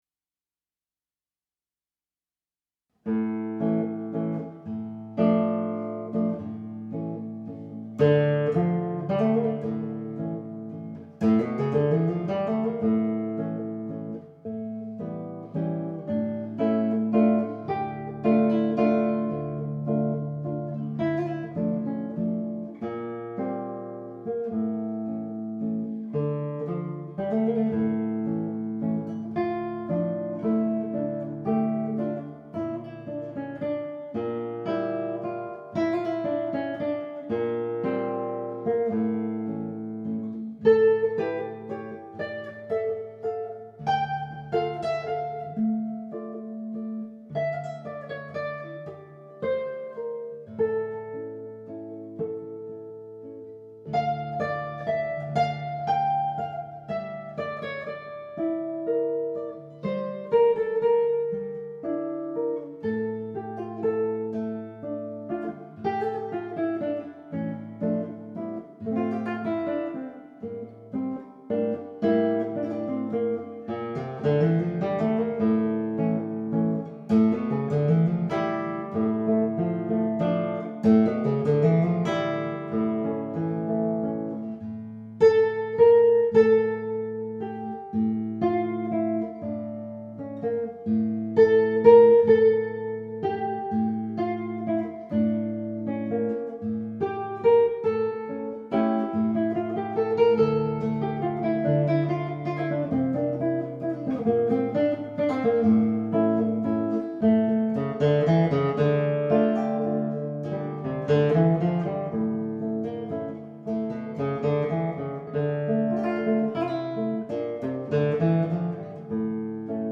Modal Scales on Each String